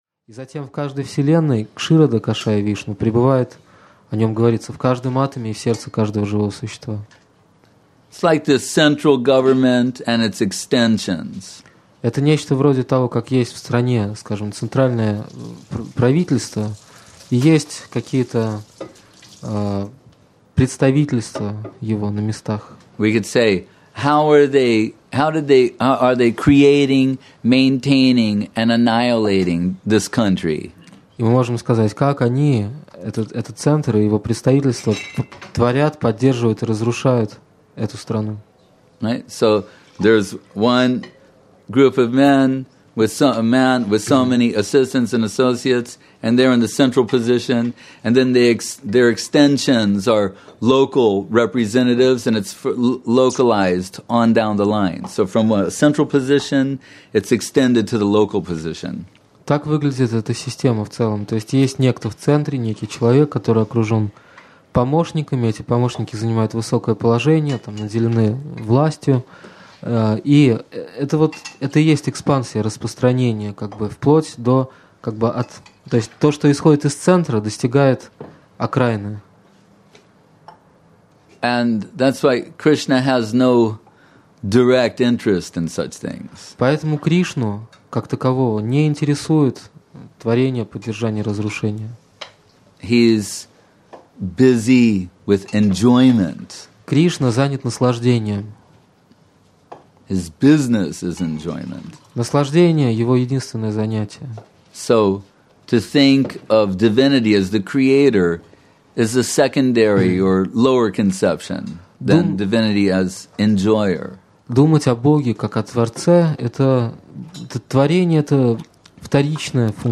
Место: Культурный центр «Шри Чайтанья Сарасвати» Москва